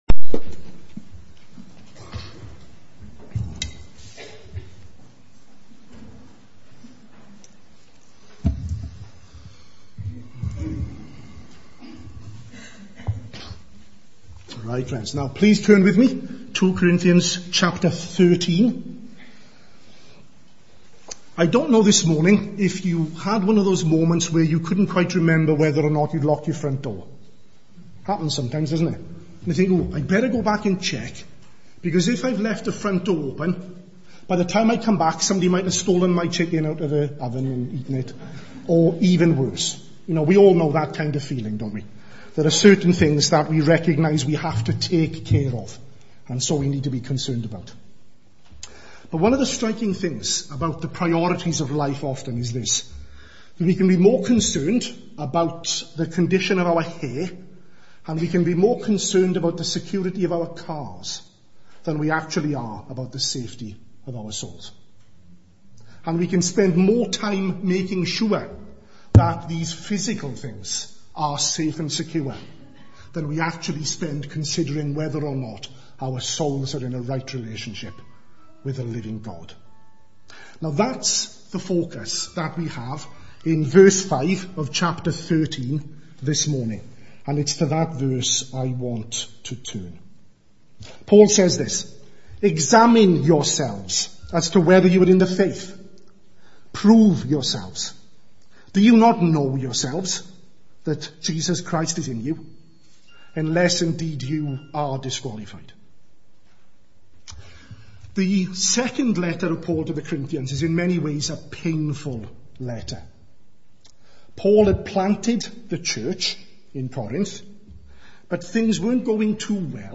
at morning service